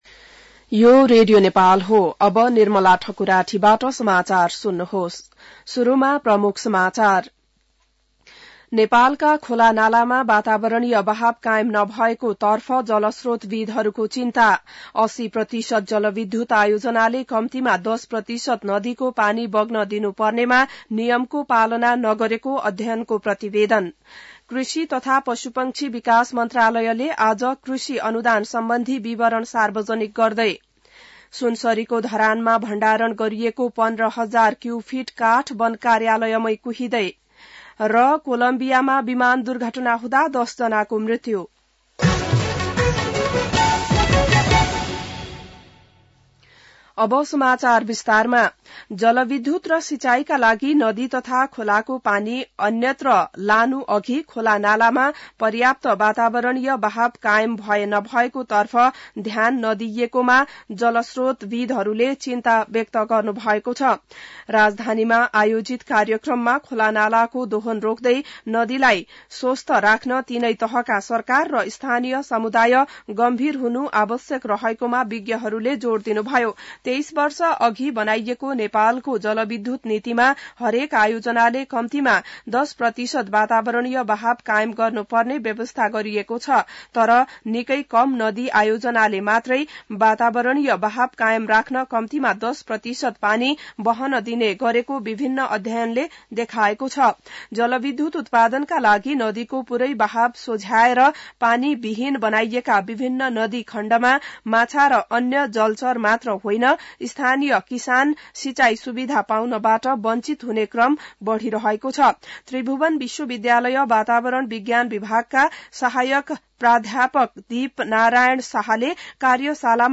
बिहान ९ बजेको नेपाली समाचार : २९ पुष , २०८१